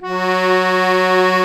F#3 ACCORD-L.wav